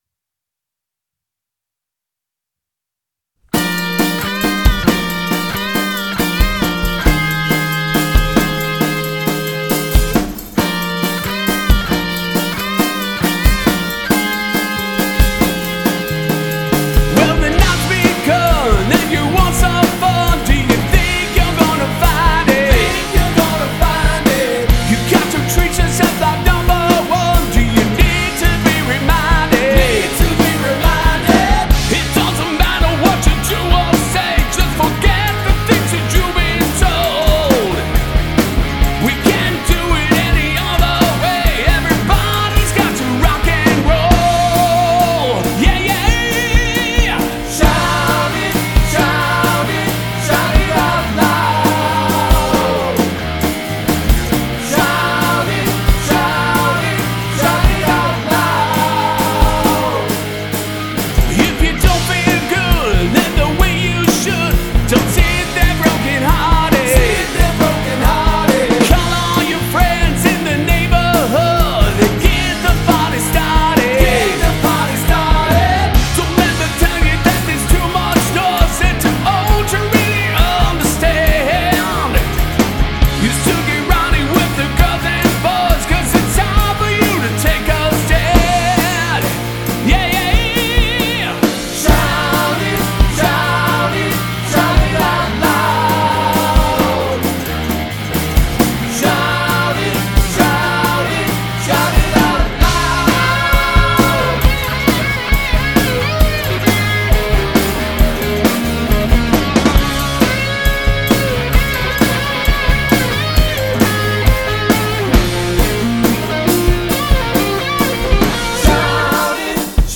(our versions of hit songs)
Vocals
guitars, backup vocals, piano, tambourine
Drums
Bass and backup vocals